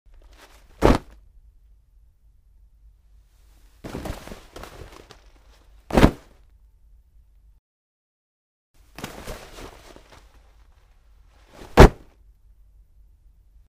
На этой странице собраны разнообразные звуки одежды: от шуршания нейлона до щелчков ремней.
Бросают куртку на пол